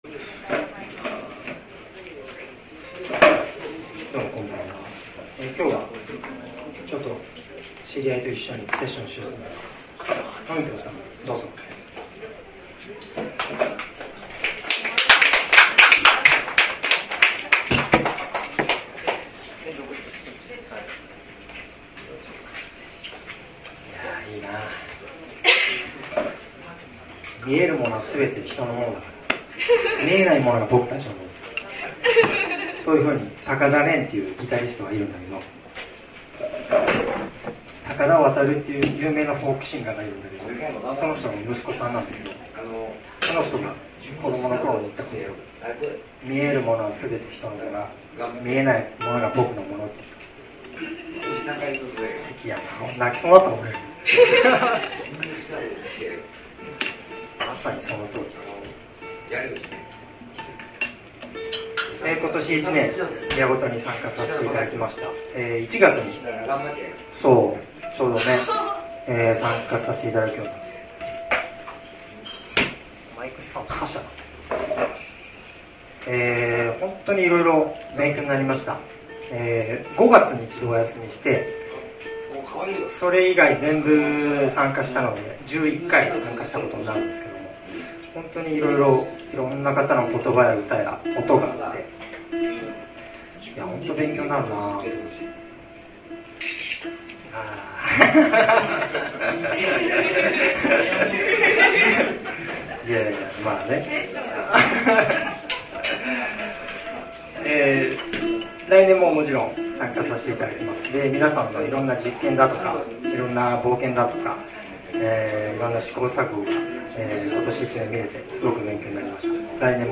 毎月第２水曜　名古屋八事　マイクパフォーマーの楽園
どの音声ファイルも、MP3、ステレオ、80kbpsです。
録音にちょこちょこ入っている男性の声がその方です（お席が録音マイクに近かったので、やや大きめに入ってしまってます……）。